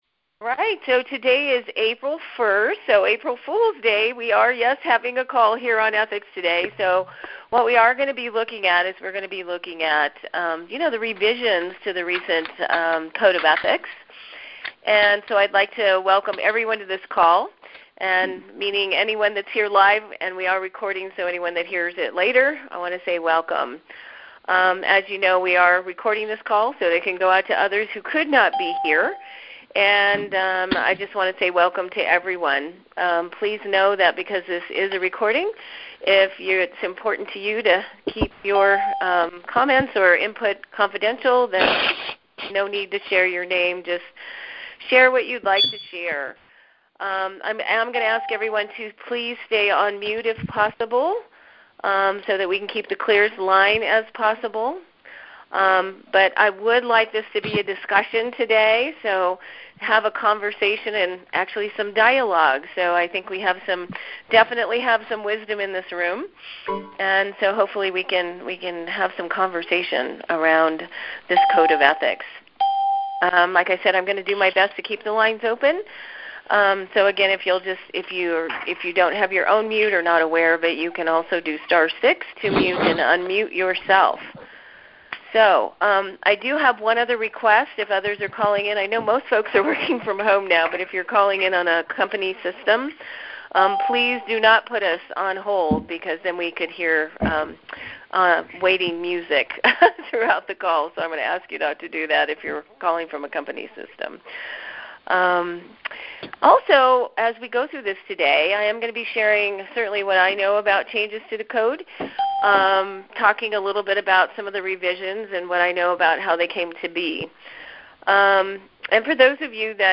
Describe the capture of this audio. This free call will be an open discussion so we may tap into the vast array of wisdom in the conference room as well as include time for Q and A throughout.